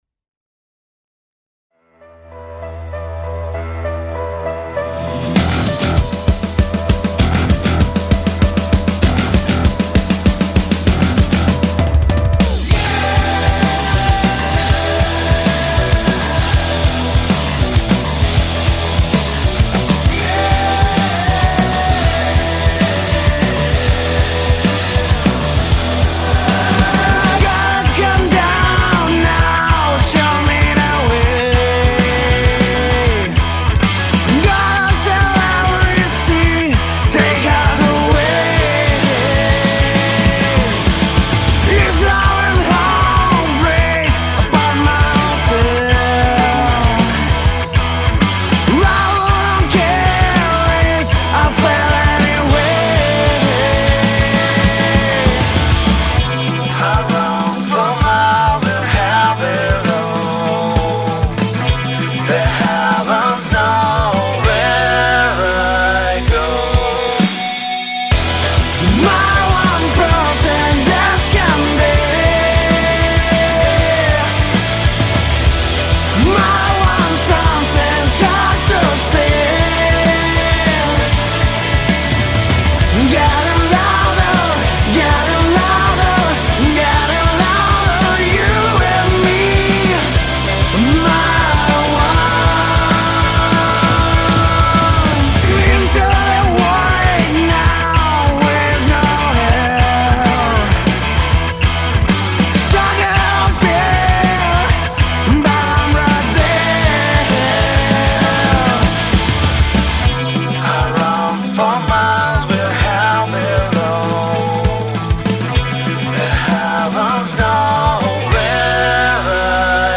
Rock,песня.
Взял на выходные поделать,песня в роке.